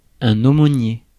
Prononciation
Prononciation France: IPA: /o.mo.nje/ Accent inconnu: IPA: /o.mɔ.nje/ Le mot recherché trouvé avec ces langues de source: français Traduction 1.